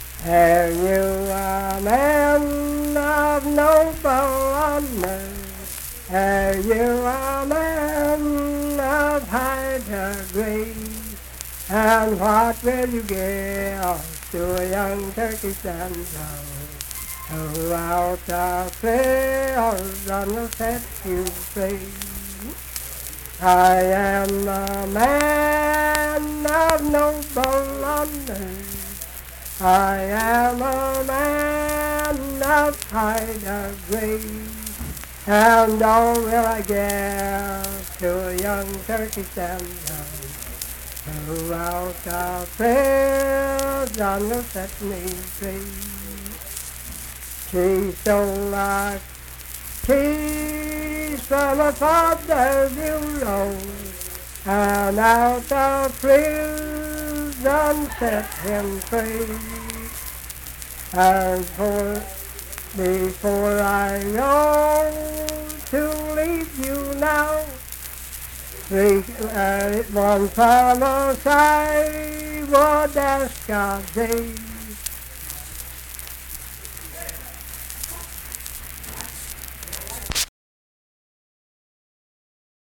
Unaccompanied vocal music
Verse-refrain 4(2-4).
Performed in Ivydale, Clay County, WV.
Voice (sung)